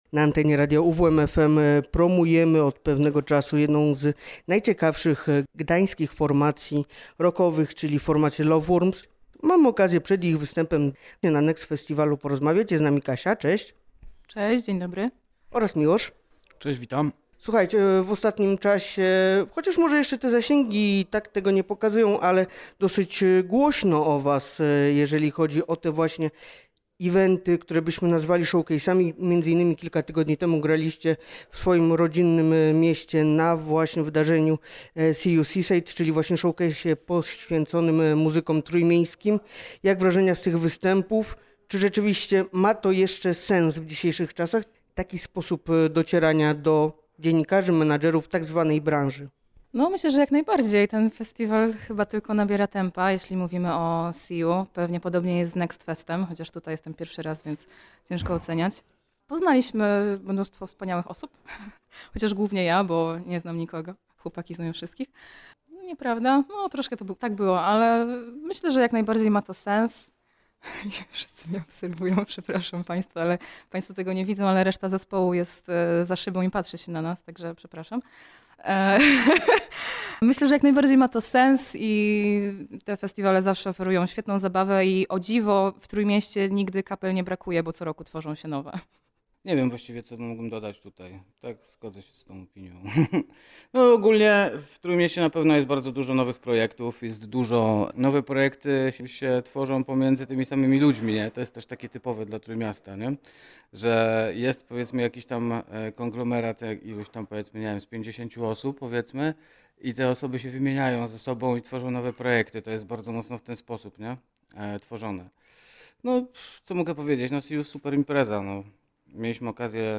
Z Loveworms rozmawiał